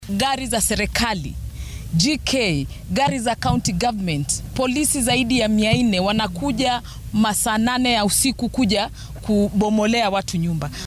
Millicent omanga oo aqalka sare ee dalka si magacaabid ah ku gashay sidoo kalena xubin ka ah guddiga dowlad wadaajinta ayaa ka hadashay boobka sharci darrada ah ee dhulalka dowladda.
Senatar-Millicent-Omanga.mp3